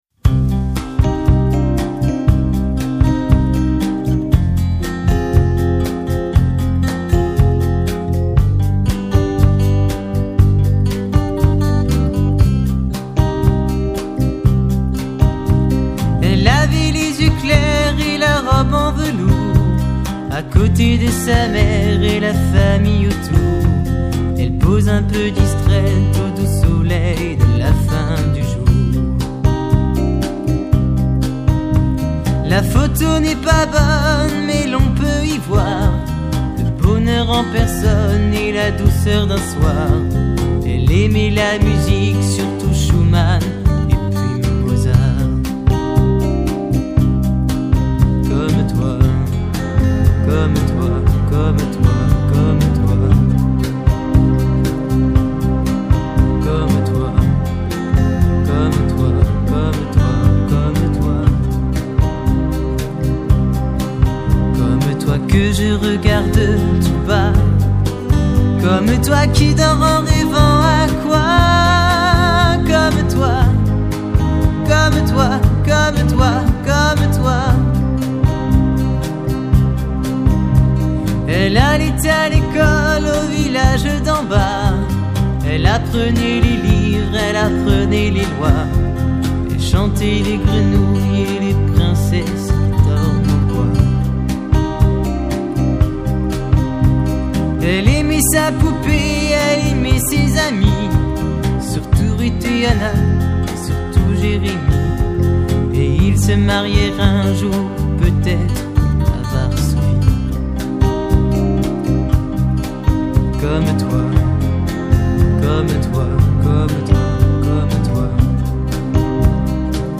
basse
batterie
guitares
violon